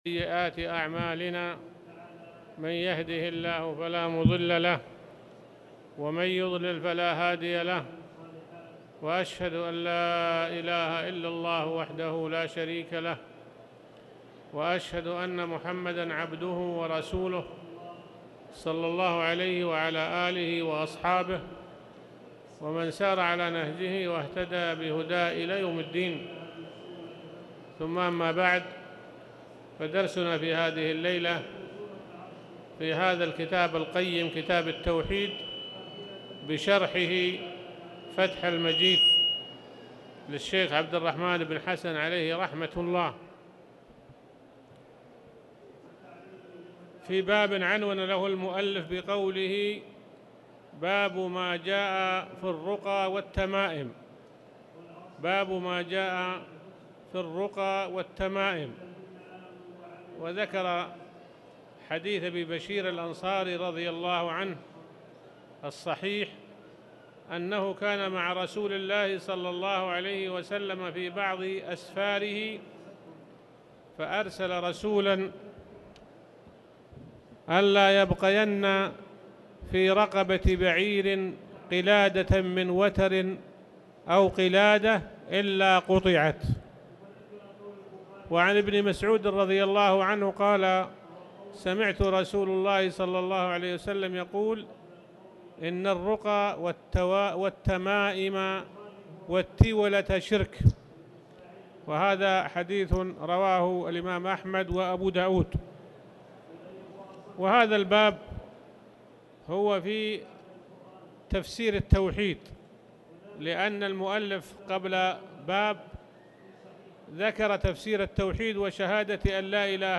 تاريخ النشر ١٤ شوال ١٤٣٧ هـ المكان: المسجد الحرام الشيخ